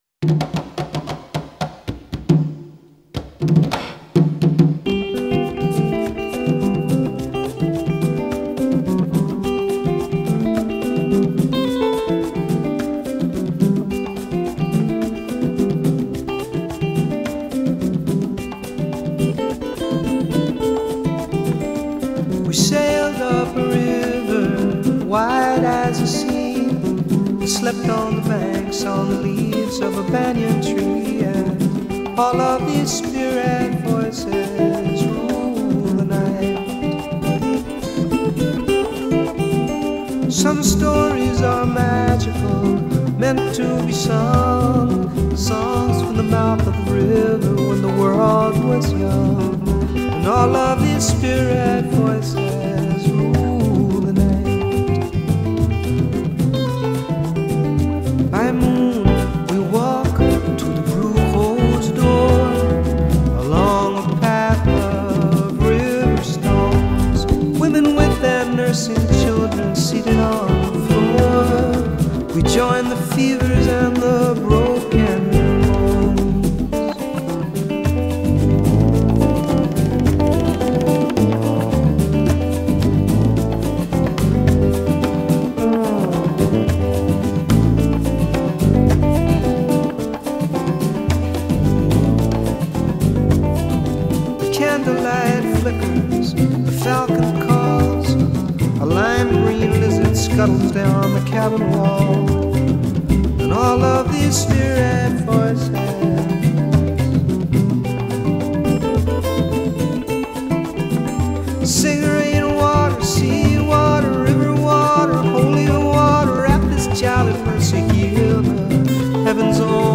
guitar lick